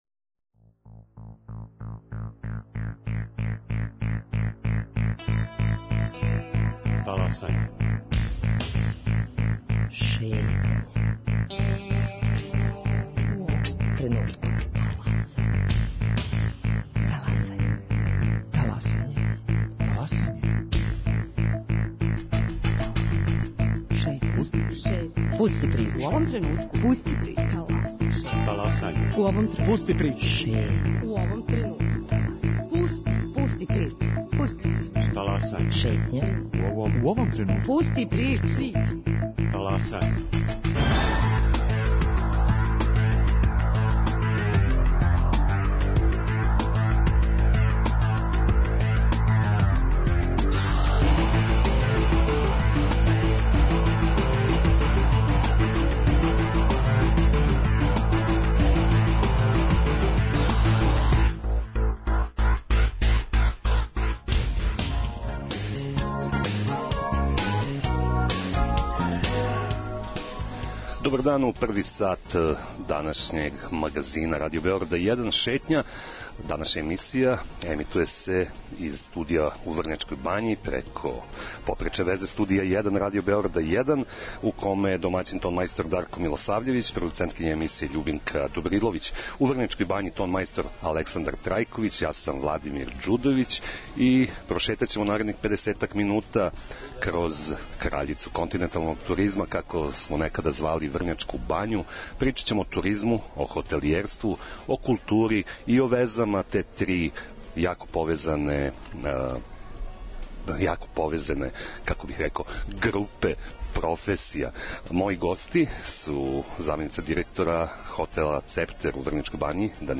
Емисију реализујемо из Врњачке бање, где се одржава 41. Фестивал филмског сценарија. Током пет фестивалских дана (од 13. до 17. августа) публика ће у званичном програму имати прилику да види петнаест нових домаћих филмских остварења, који ће се такмичити за награде ФФС.